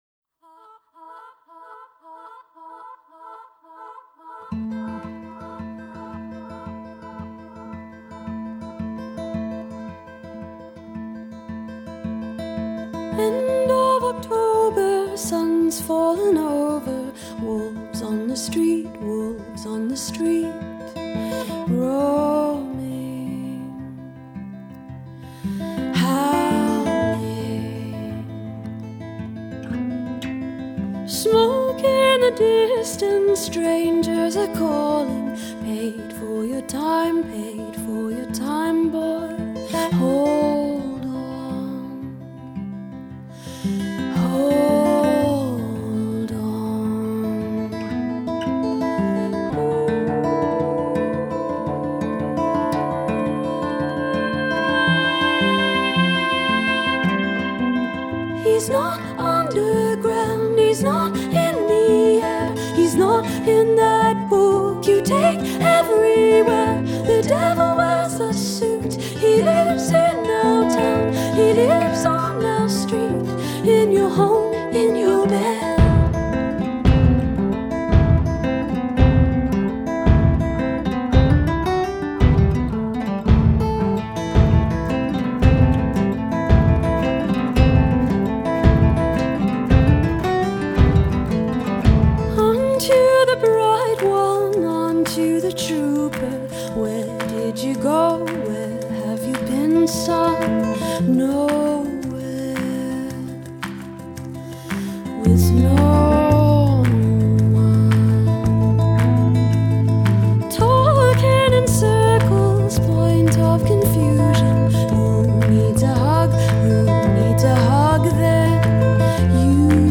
haunting & smart, w/ Celtic air
With the air of Celtic folk music about it
is a haunting piece of smart, beautifully-crafted pop